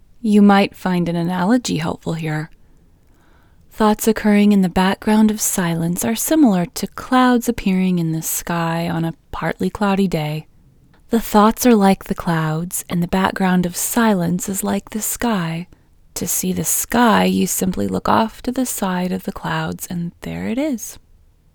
QUIETNESS Female English 7
Quietness-Female-7-1.mp3